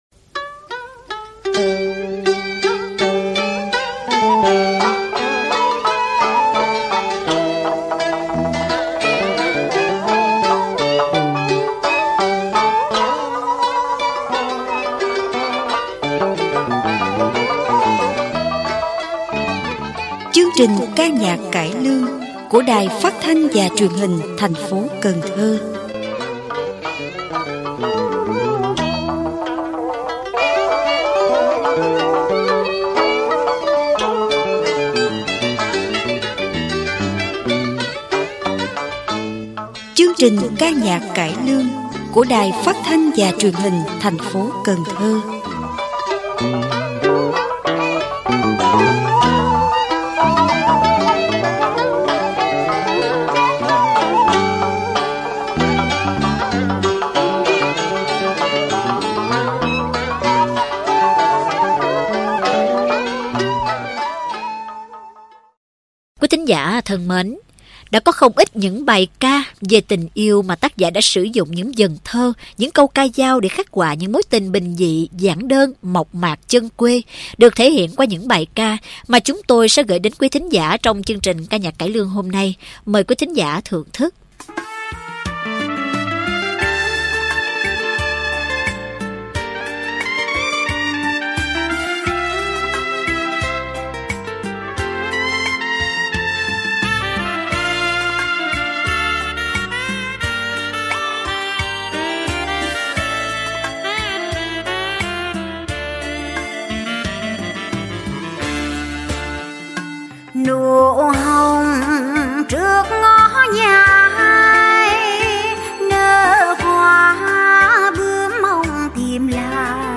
Ca nhạc cải lương: Nụ hồng